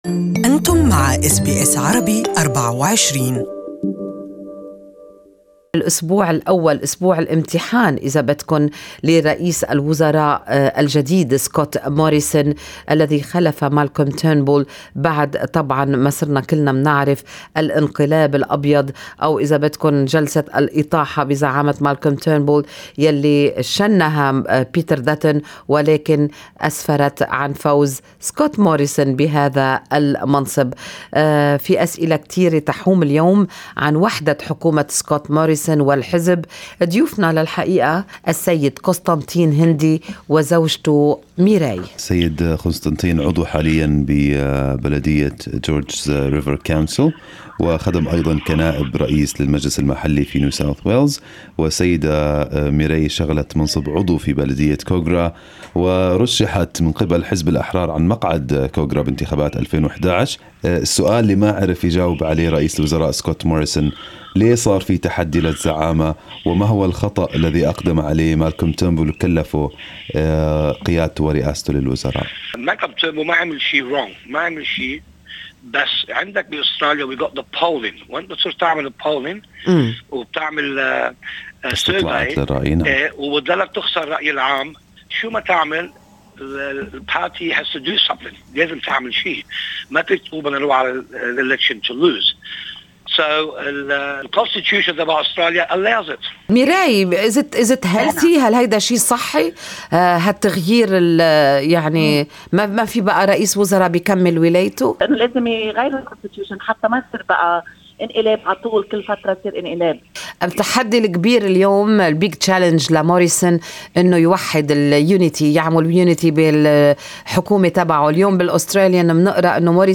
Good Morning Australia interviewed George's River Council member Con Hindi